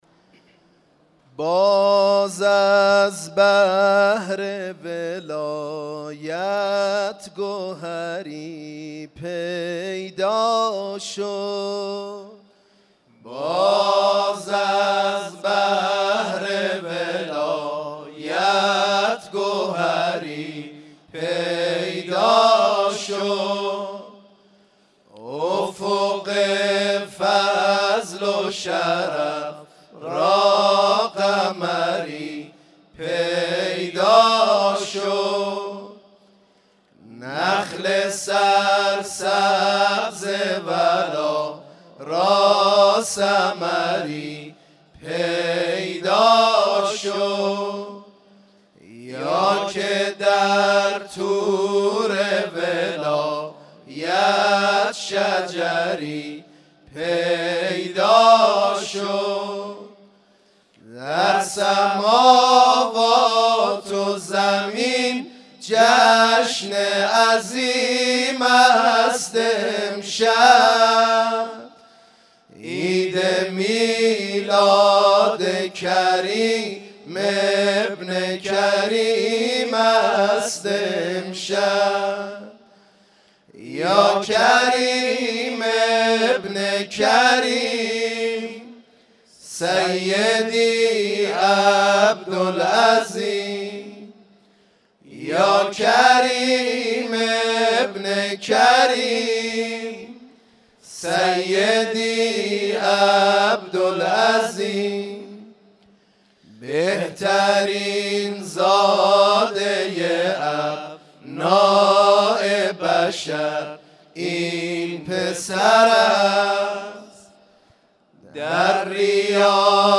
تواشیح عبدالعظیم